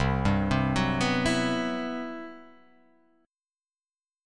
CM#11 chord